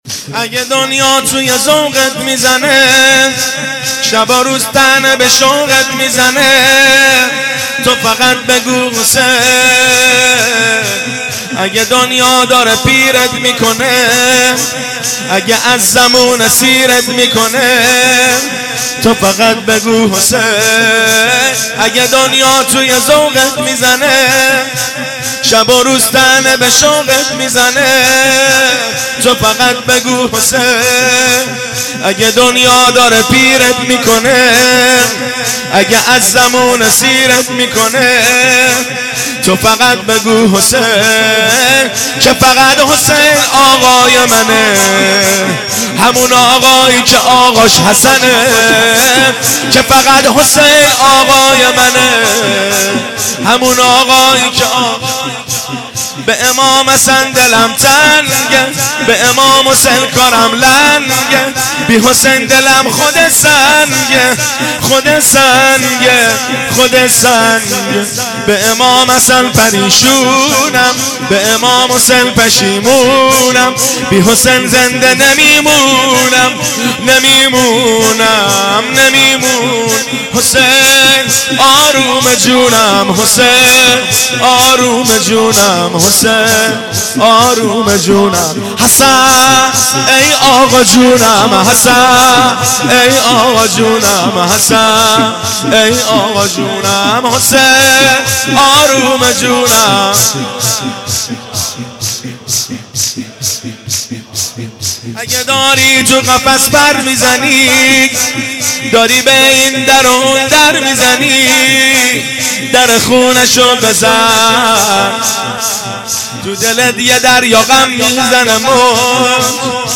مراسم شب ششم محرم